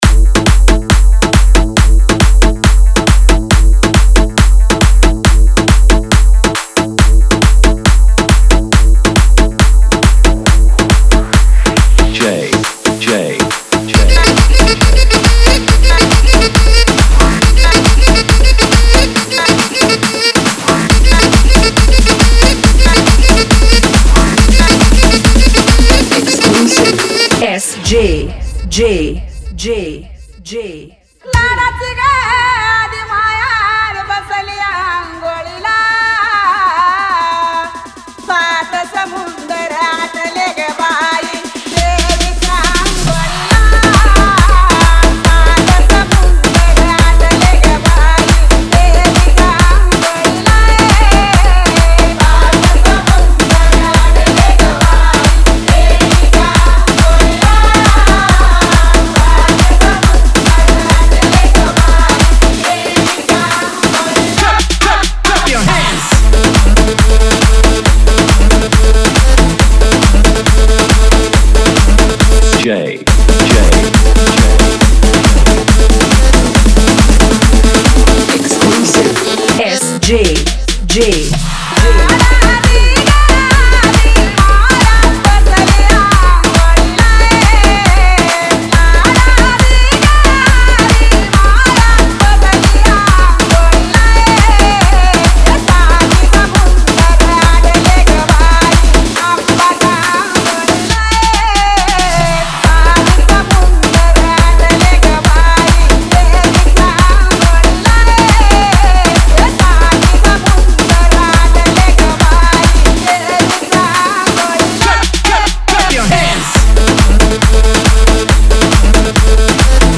Navratri Dj Remix Song Play Pause Vol + Vol -